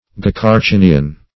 Search Result for " gecarcinian" : The Collaborative International Dictionary of English v.0.48: Gecarcinian \Ge`car*cin"i*an\ (j[=e]`k[aum]r*s[i^]n"[i^]*an), n. [Gr. gh^ earth + karki`nos crab.]